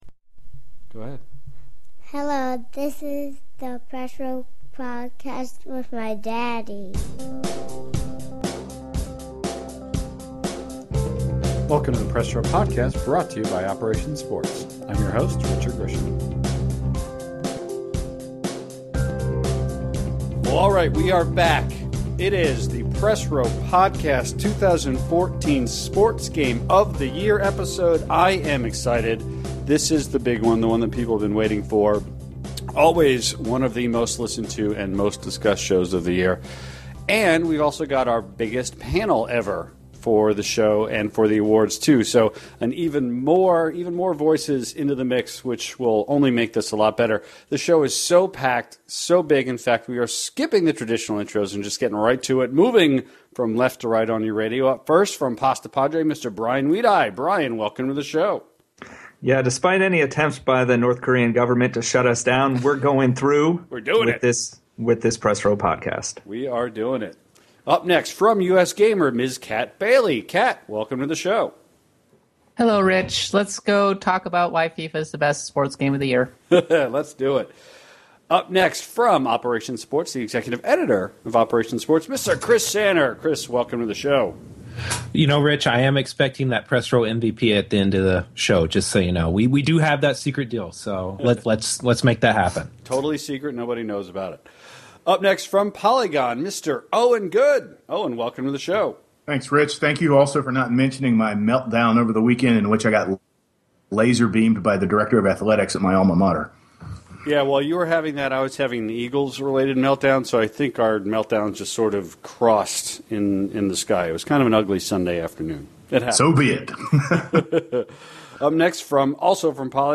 The votes have been tallied and the winners are announced. As always, none of the panelists are aware of who wins coming into the show, and the discussions leading up to the unveiling and the reactions afterwards are the best part of it.